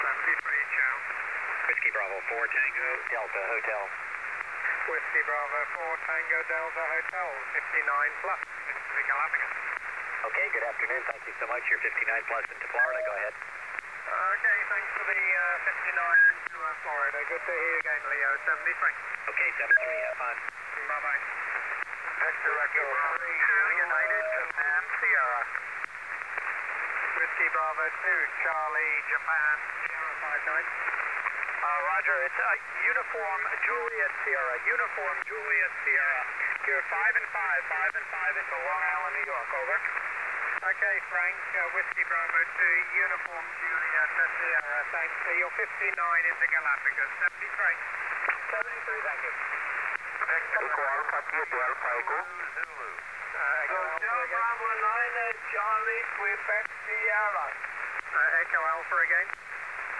18MHz SSB